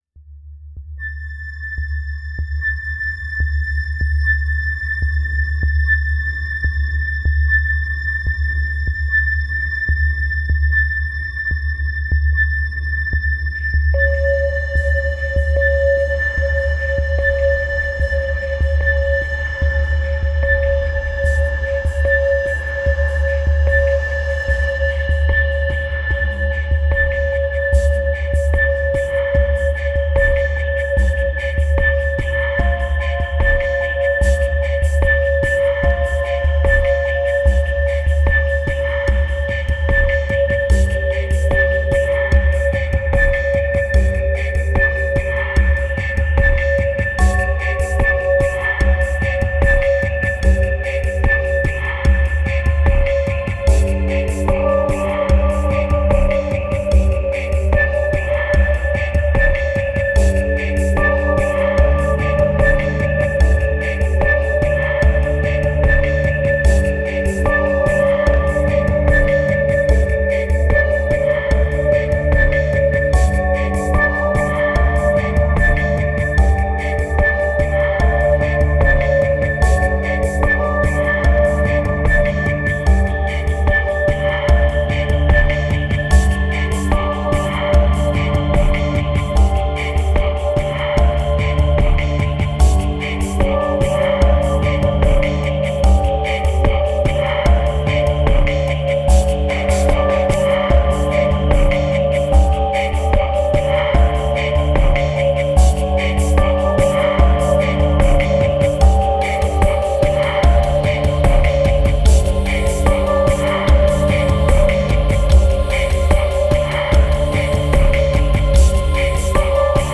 1632📈 - 3%🤔 - 74BPM🔊 - 2014-05-04📅 - -212🌟